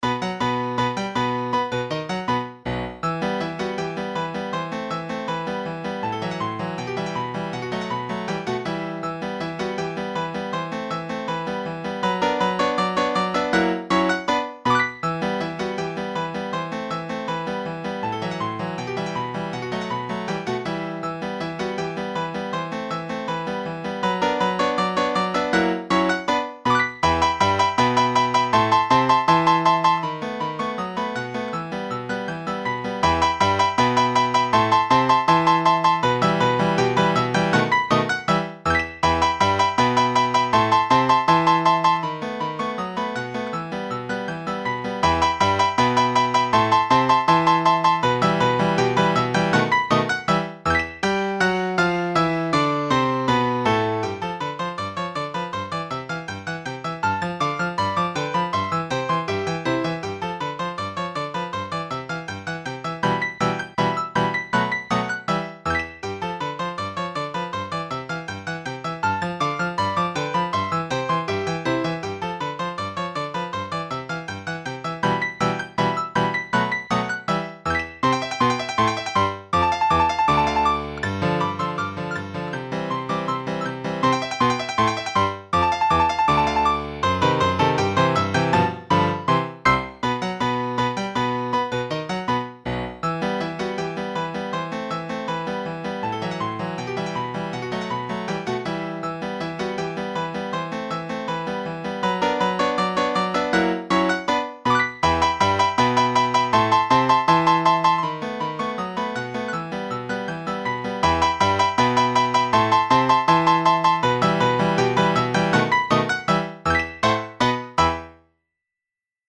この曲のファイルはピアノ版で作成してあります。テンポは少々早め。